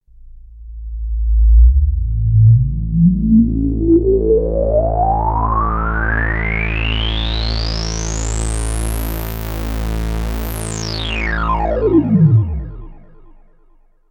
This feature is especially useful when CV outputs are controlled by 7bit velocity or CC values, as it gets use of the full 12bit resolution and reduces zipper noise dramatically.
CC filter sweep without interpolation
mbcv_without_interpolation.mp3